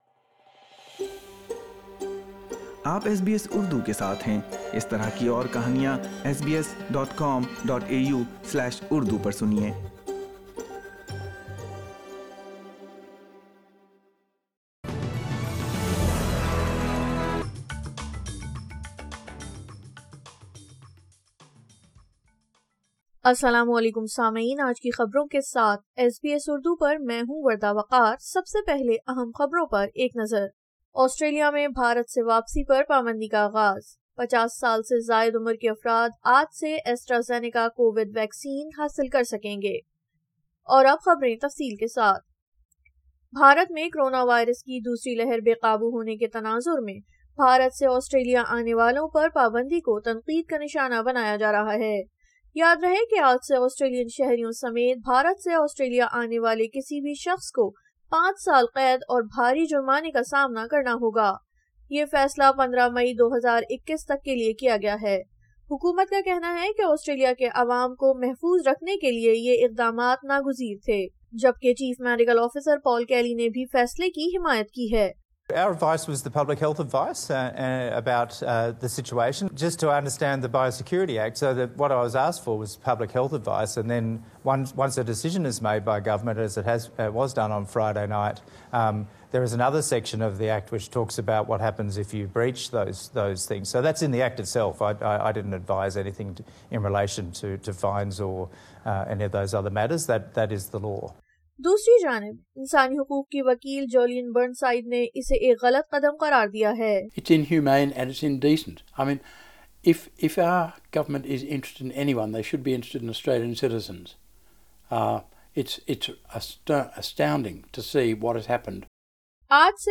Urdu news 03 May 2021